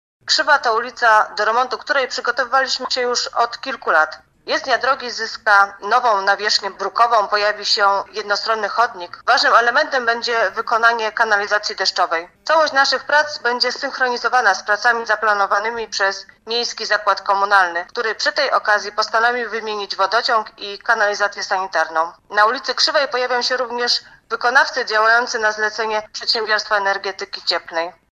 A w ramach zadania ulica Krzywa w Stalowej Woli ma zyskać nie tylko nową nawierzchnię. Mówiła o tym wiceprezydent Renata Knap